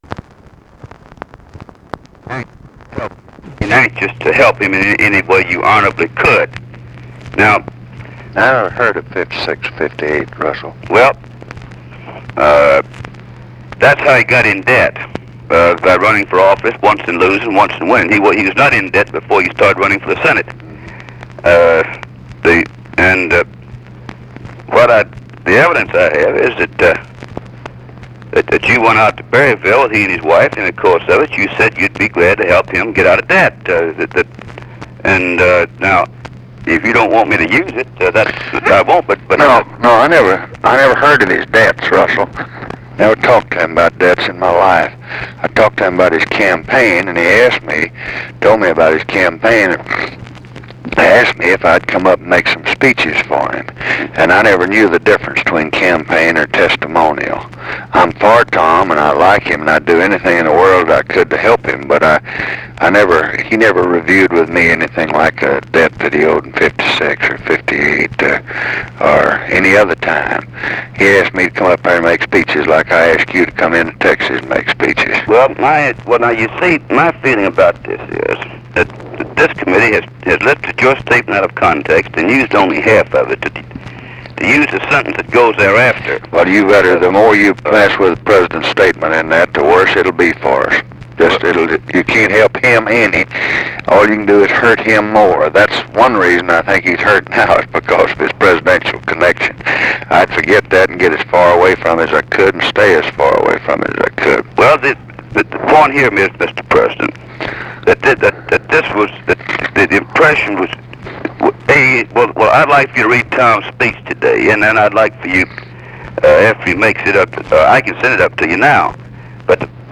Conversation with RUSSELL LONG, June 14, 1967
Secret White House Tapes